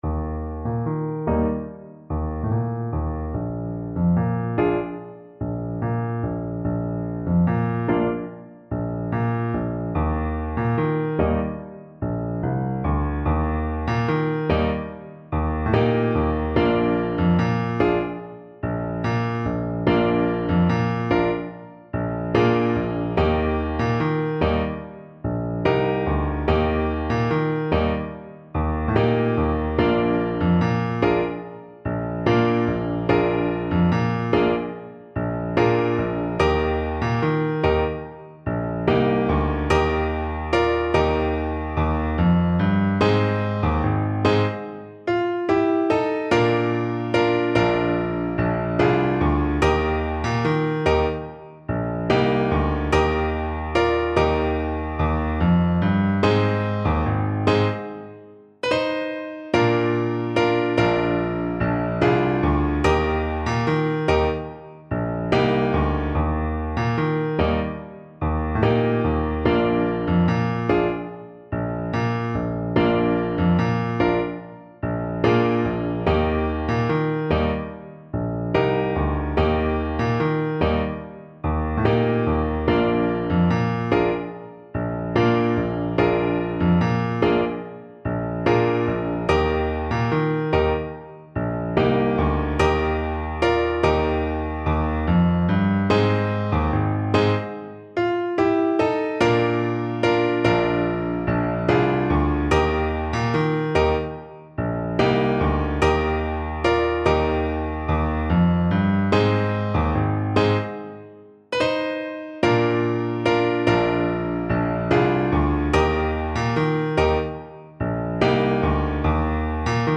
Alto Saxophone
A traditional folk song from Kenya
4/4 (View more 4/4 Music)
Allegro =c.110 (View more music marked Allegro)